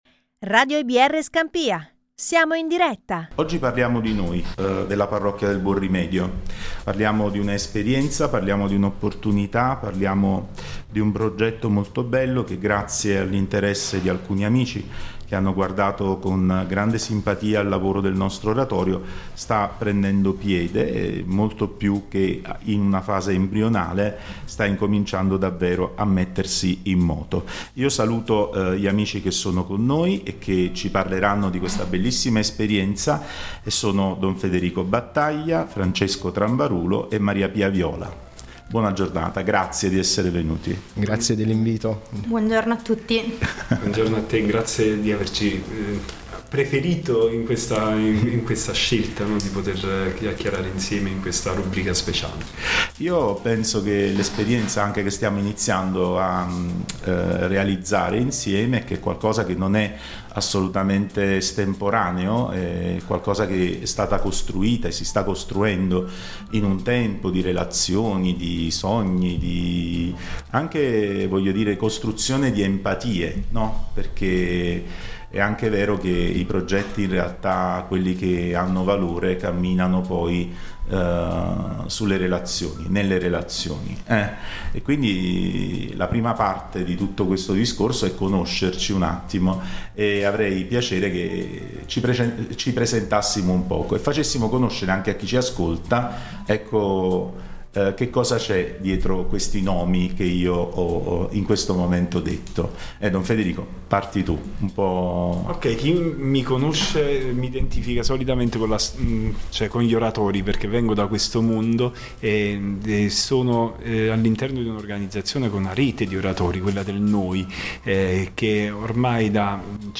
Il 16 luglio abbiamo avuto in studio